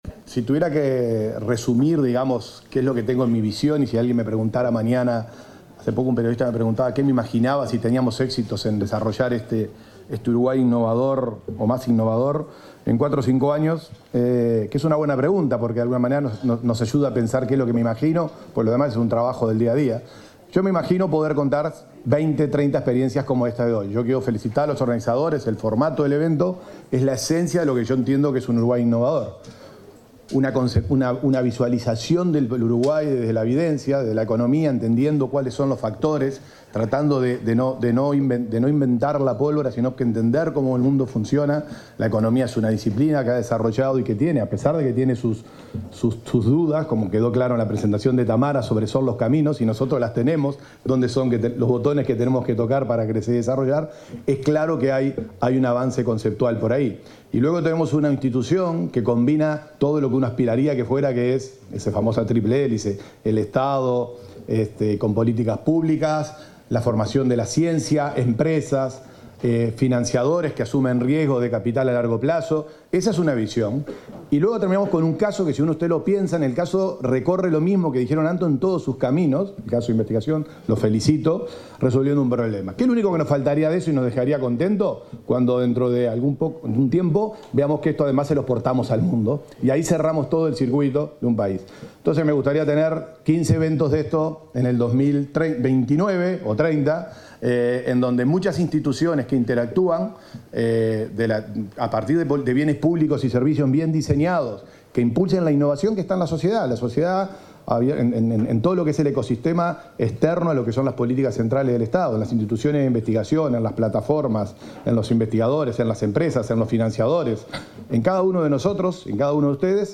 Palabras de autoridades en acto del Institut Pasteur de Montevideo
Palabras de autoridades en acto del Institut Pasteur de Montevideo 07/05/2025 Compartir Facebook X Copiar enlace WhatsApp LinkedIn Este miércoles 7 en el Club de Golf de Montevideo, el asesor de ciencia y tecnología de Presidencia de la República, Bruno Gili y el ministro de Ganadería, Alfredo Fratti, expusieron, durante la presentación de una vacuna contra la garrapata, desarrollada por el Institut Pasteur de Montevideo.